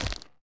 Minecraft Version Minecraft Version 1.21.5 Latest Release | Latest Snapshot 1.21.5 / assets / minecraft / sounds / mob / creaking / creaking_sway1.ogg Compare With Compare With Latest Release | Latest Snapshot
creaking_sway1.ogg